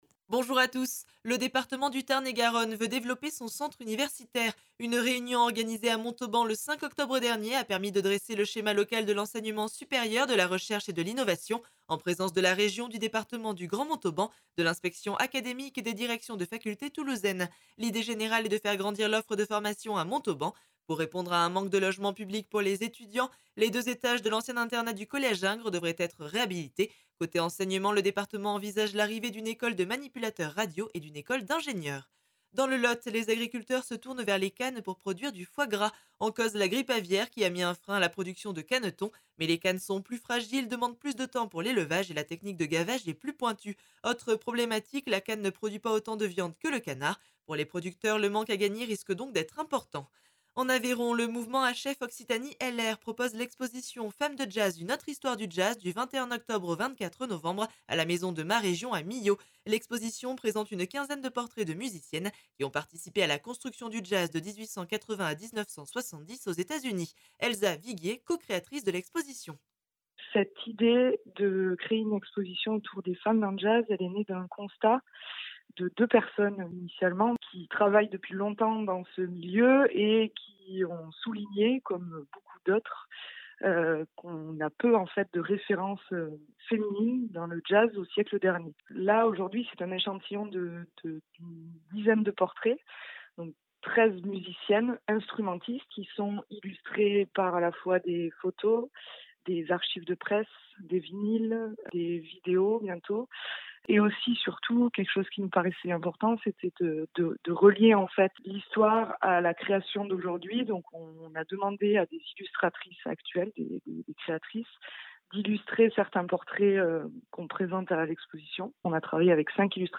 L’essentiel de l’actualité de la région Occitanie en 3 minutes. Une actualité centrée plus particulièrement sur les départements de l’Aveyron, du Lot, du Tarn et du Tarn & Garonne illustrée par les interviews de nos différents services radiophoniques sur le territoire.